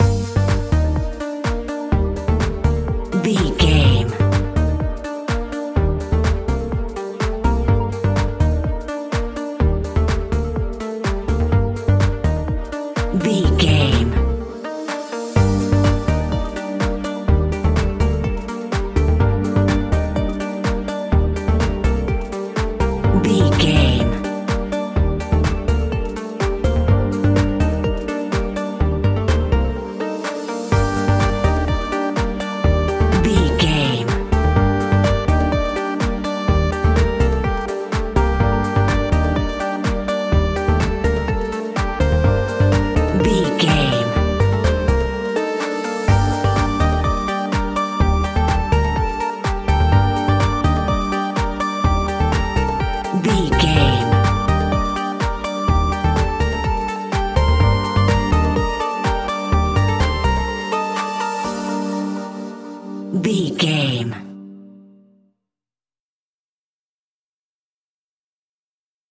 Aeolian/Minor
groovy
uplifting
driving
energetic
repetitive
drum machine
synthesiser
bass guitar
funky house
deep house
nu disco
upbeat
instrumentals